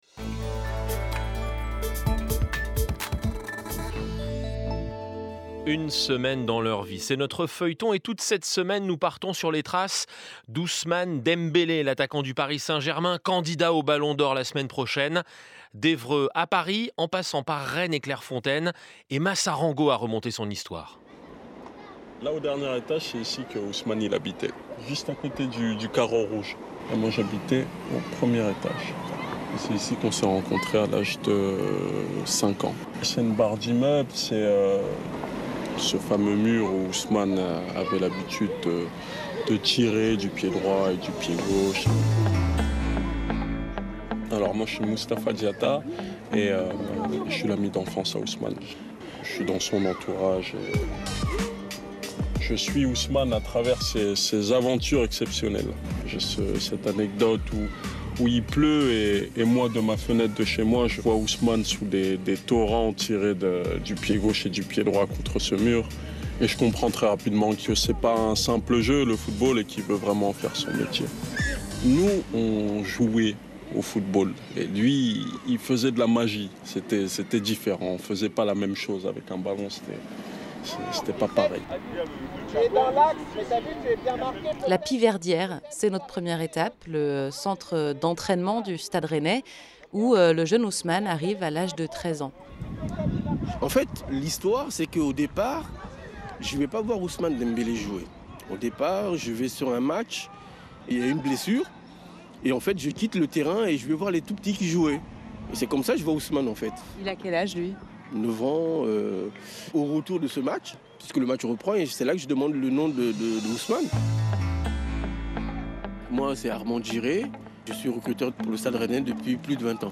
Catégorie radio – Radio France –